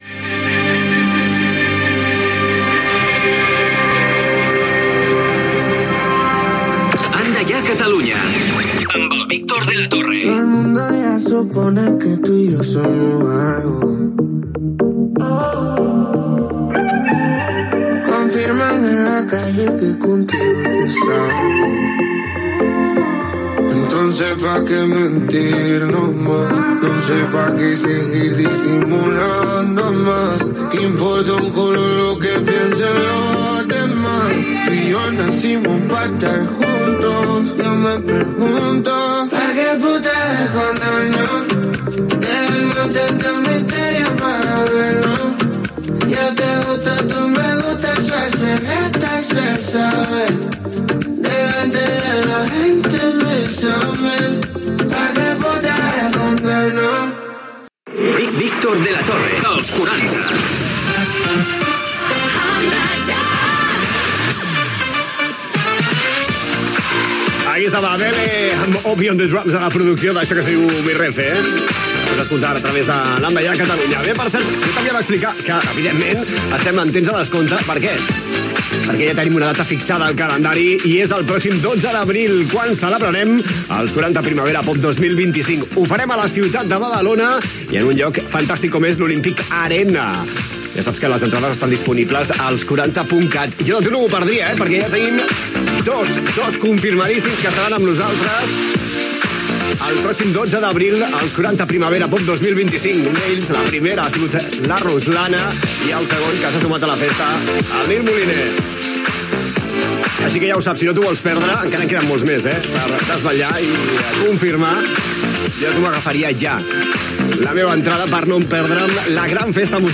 Indicatiu del programa, tema musical, indicatiu, anunci de Los 40 Primavera Pop a Badalona, tema musical.
Musical
FM